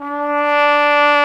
Index of /90_sSampleCDs/Roland L-CD702/VOL-2/BRS_Cornet/BRS_Cornet 2